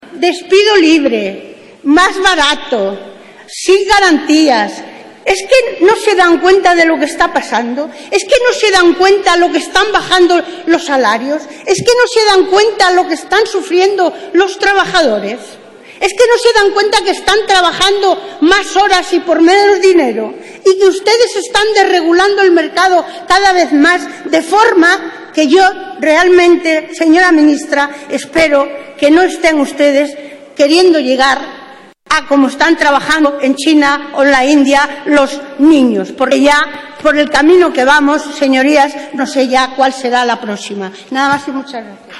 Isabel López y Chamosa en el pleno le explica a la ministra de Empleo lo que significa la reforma laboral del PP 12/09/2013